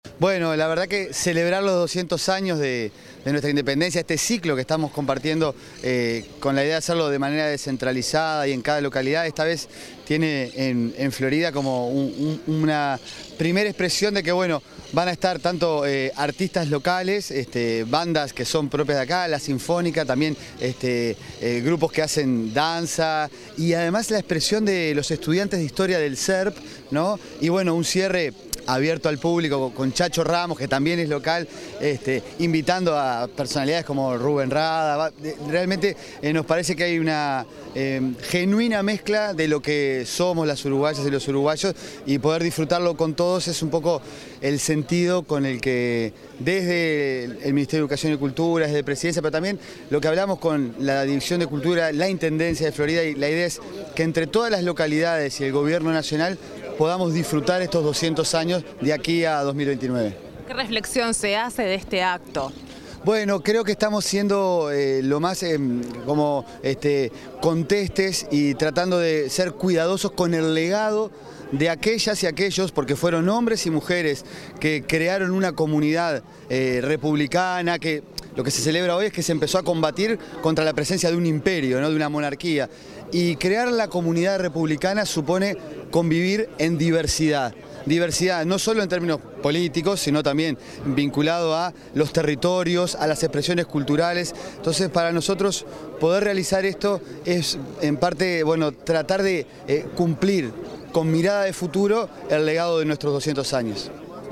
Declaraciones del director nacional de Educación, Gabriel Quirici